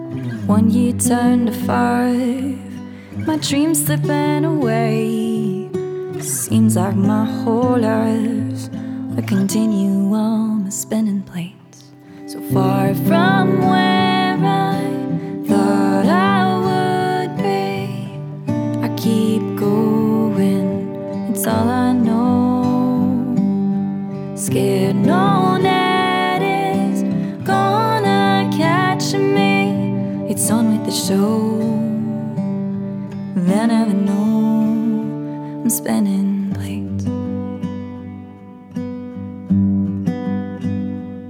Acoustic Soul / Pop / Rock
stripped-down feel